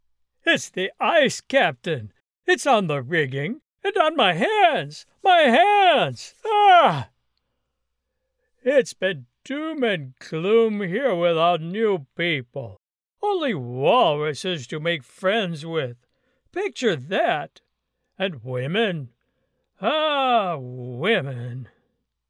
Sailor Character
English - Midwestern U.S. English
Middle Aged
My very quiet home studio is equipped with a Neumann TLM 103 microphone and other professional gear with high speed internet for smooth audio delivery!